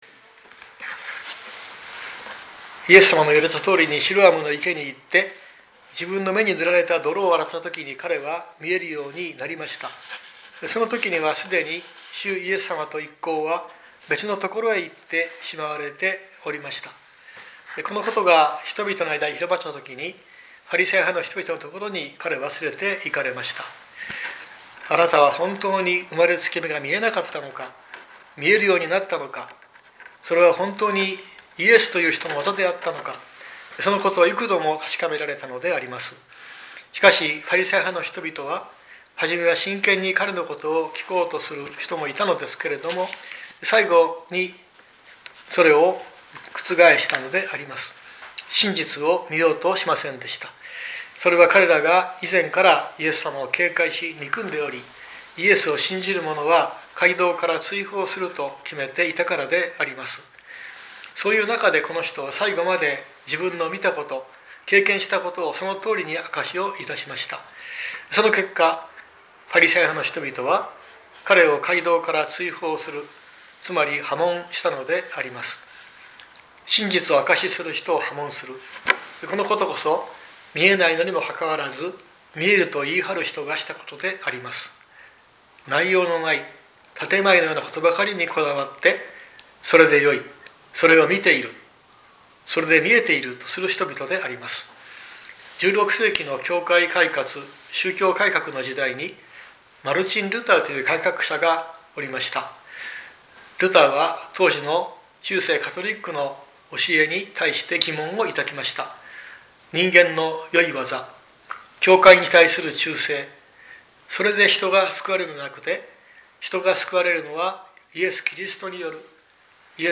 日曜朝の礼拝